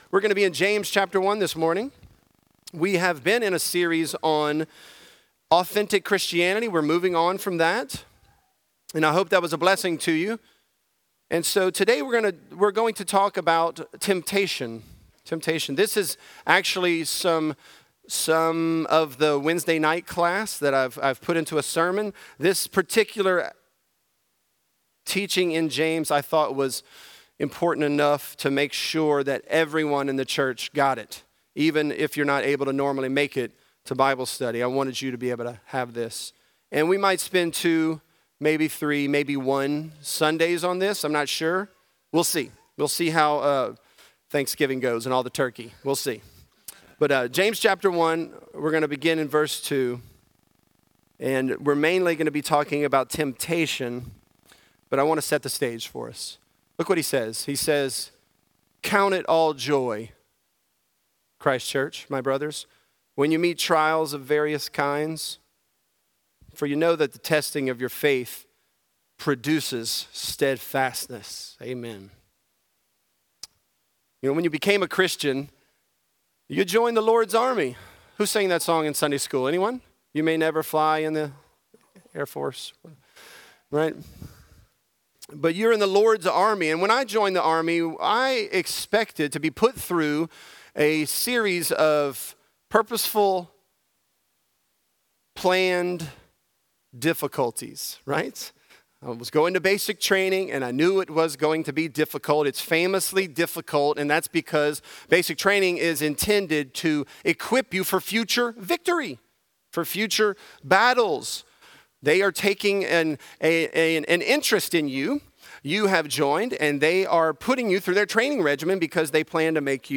Temptation: Fighting Temptation | Lafayette - Sermon (James 1)